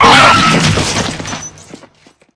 flesh_death_1.ogg